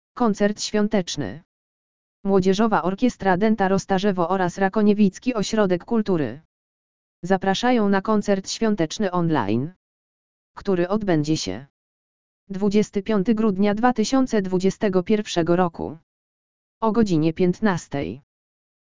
audio_lektor_koncert_swiateczny!.mp3